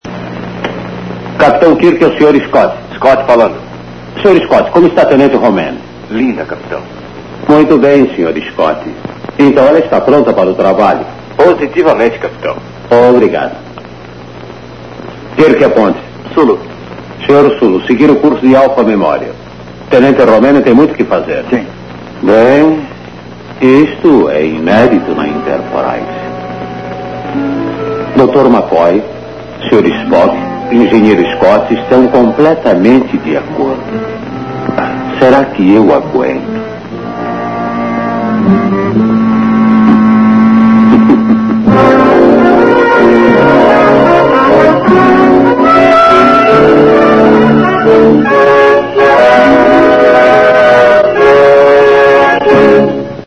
Kirk, Spock e McCoy num final feliz em